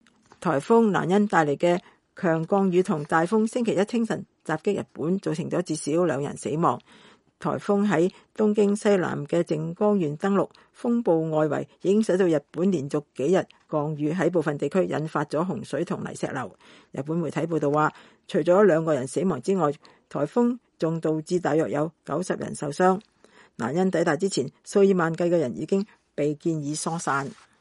10月22日颱風蘭恩為日本帶來的強降雨和大風